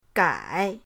gai3.mp3